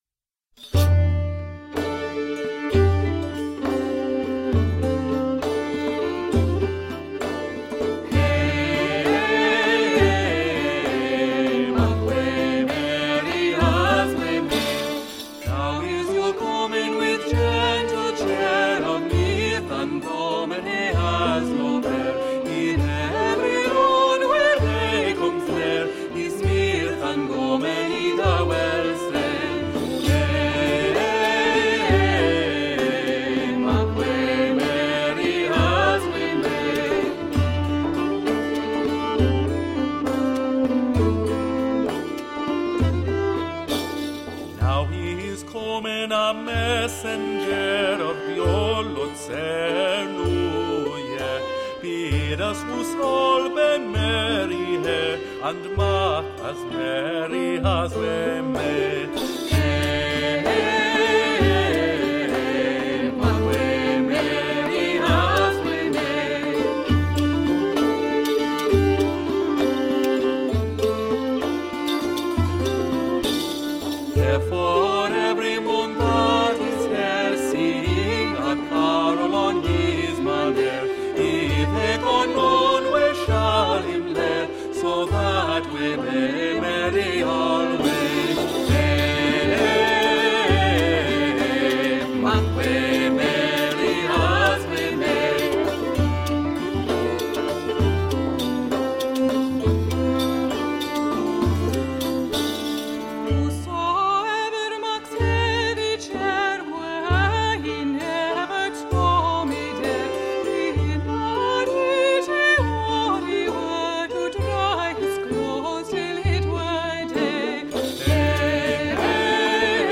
Our annual fruitcake of wintery music, poetry, & other holiday goodies!